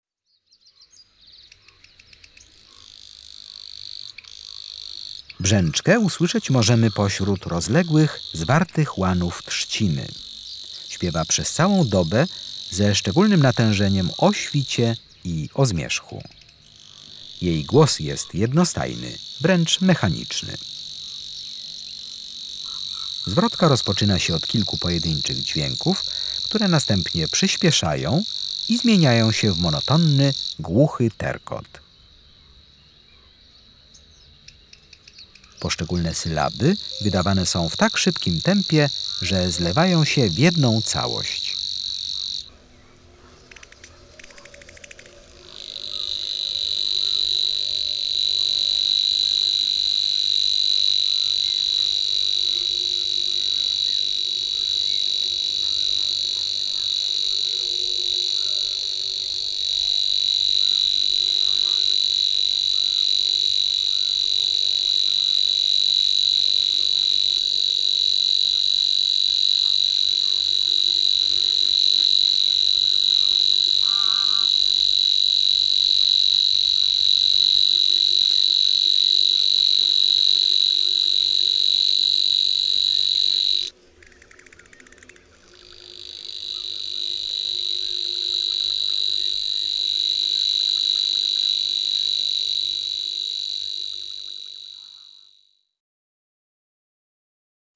29Brzeczka.mp3